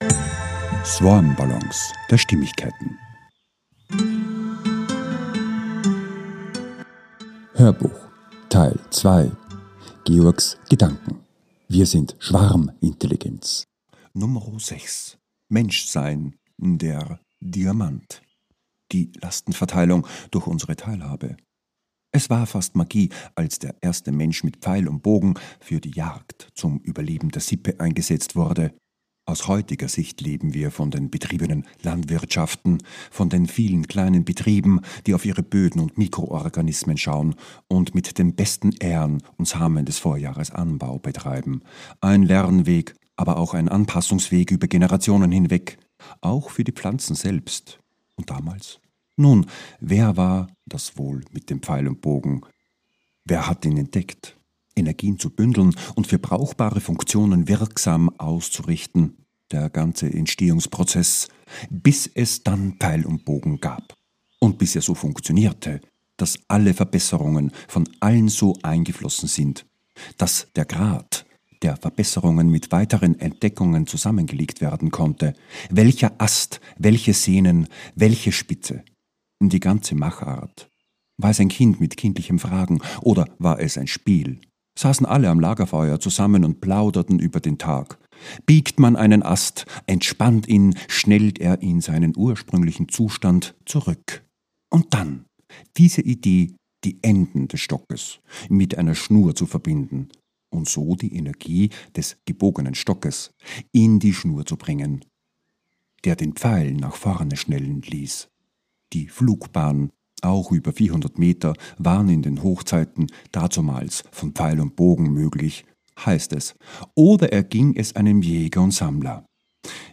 HÖRBUCH TEIL 2 - 06 - WIR SIND SCHWARMINTELLIGENZ 2 - MENSCHSEIN - Der Diamant - 23.01.26, 15.55 ~ SwarmBallons A-Z der Stimmigkeit Podcast